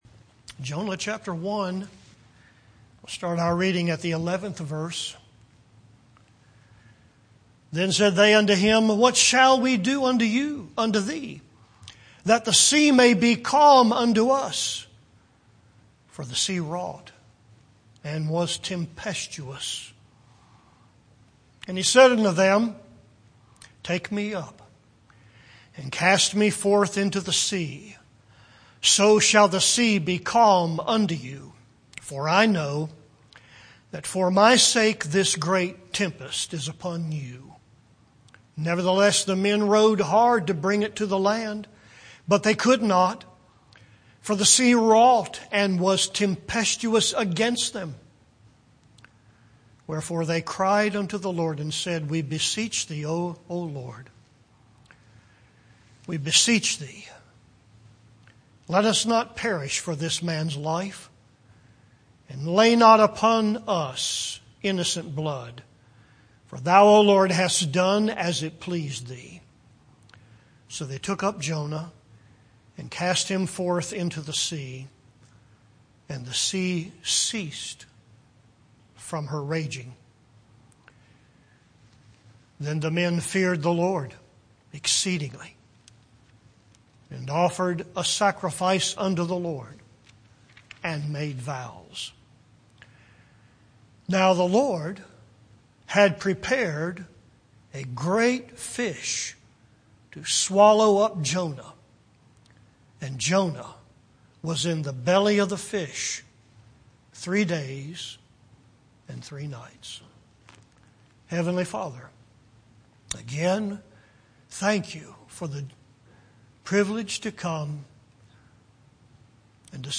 Grace Fellowship Baptist Church, Arden, NC